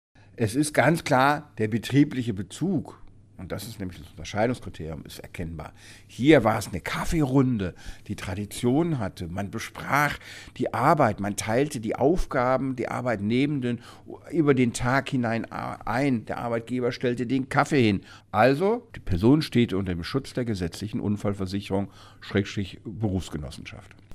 DAV, O-Töne / Radiobeiträge, Ratgeber, Recht, , , , , , , ,